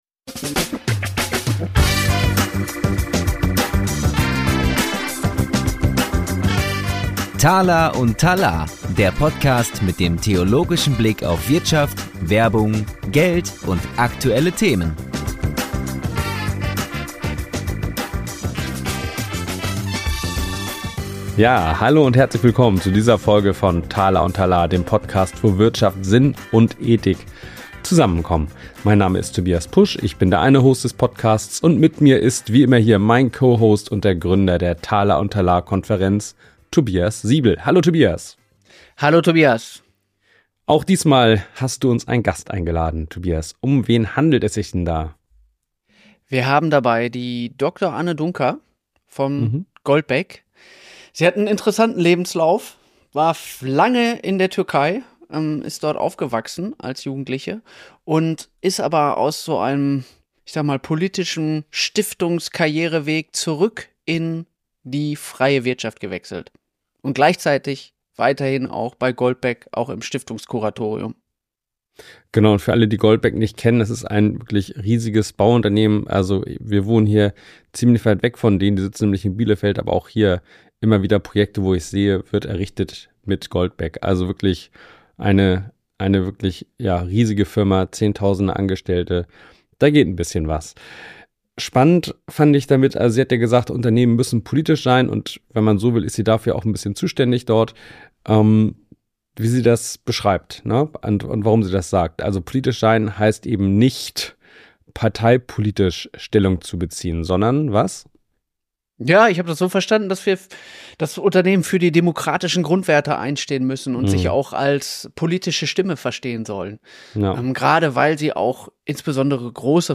Warum müssen Unternehmen politisch sein und was bedeutet das? Gespräch